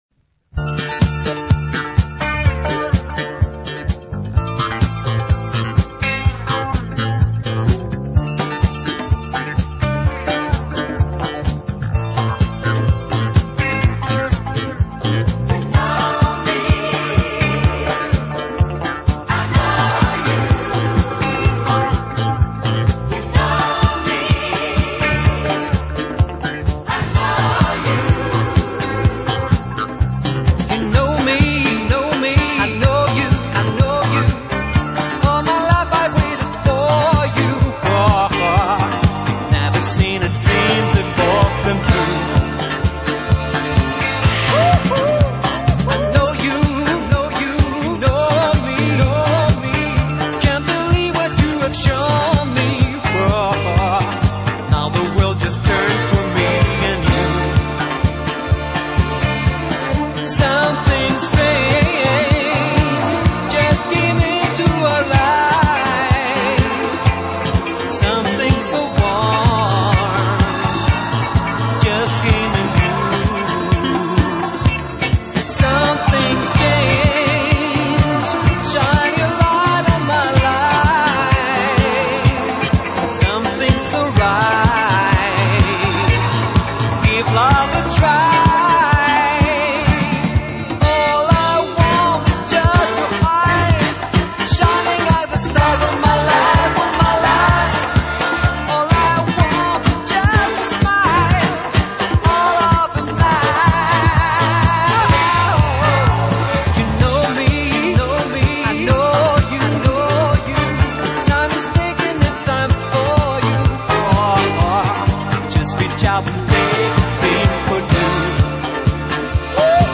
RARE DISCO 12 INCH